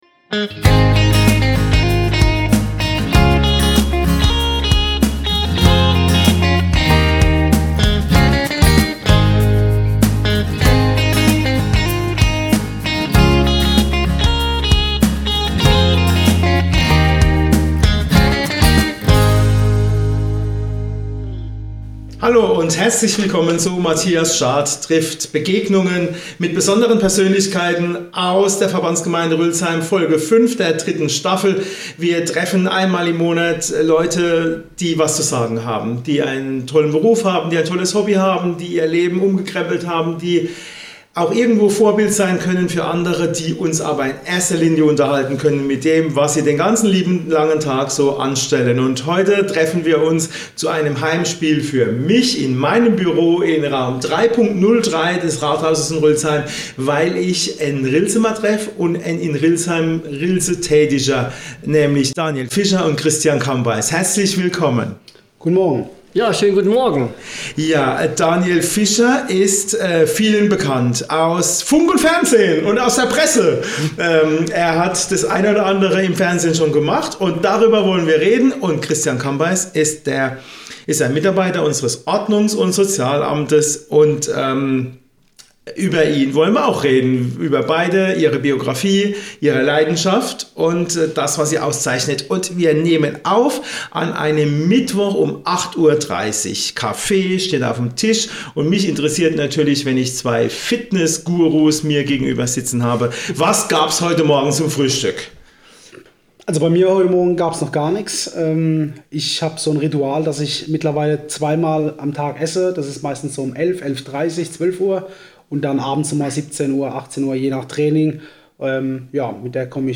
Die drei sprechen über die Arbeit des kommunalen Vollzugsdienstes, den Kampf gegen die Pfunde, das richtige Fitnesstraining und die Bedeutung von gesunder Ernährung für das eigene Wohlbefinden.